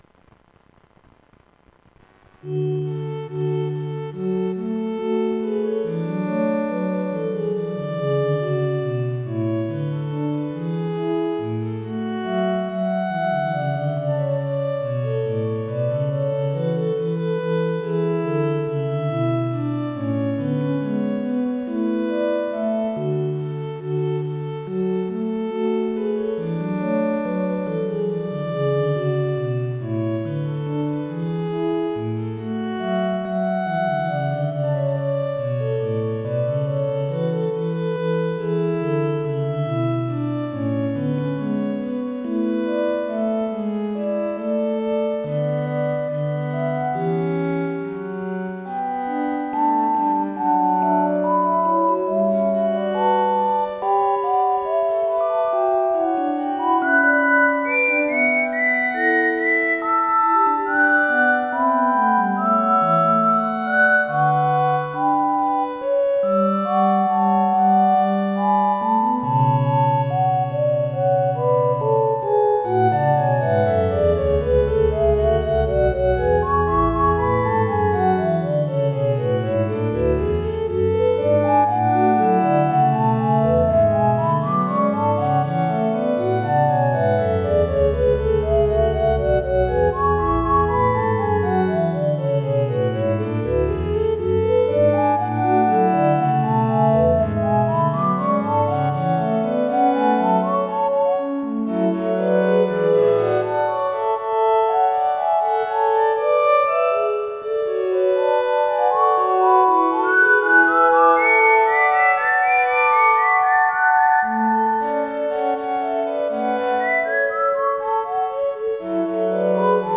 FUTURISTIC MUSIC ; POLYPHONIC MUSIC